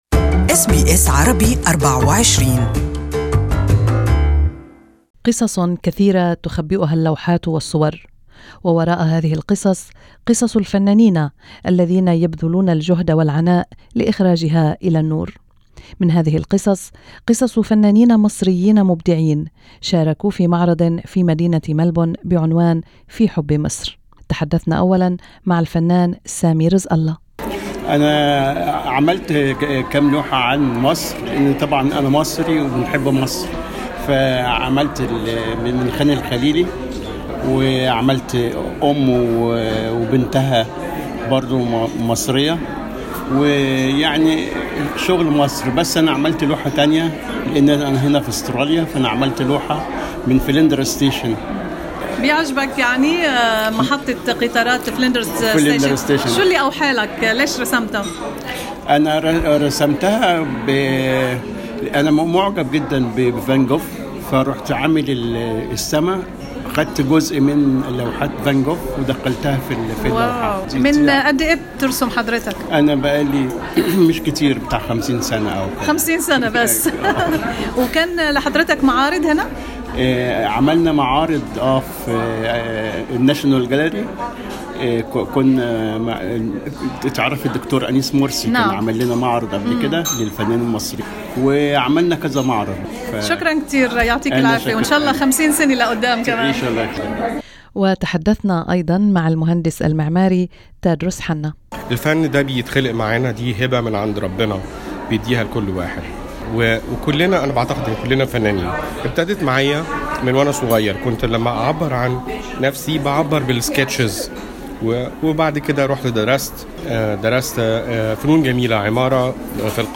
Painters draw life stories in color and lines, but they too have their own life stories. We listen here to three Egyptians painters talking about their journeys.